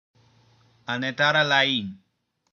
Anetaralaism (Drakona: ɔɤ̆ɾɔʊ̆ɂɔȷɤ; pronunciation:
Listeni/ˌɑˈn.tɑ.rɑ.lɑ.n/; Anetaralain lit. "Militaristism"), sometimes referred to as Nenathosinism, was a political ideology that sprouted up in the Adborthos-Yolatho Republic shortly after the Yolathosi Revolution threw out the rulers of the First Republic.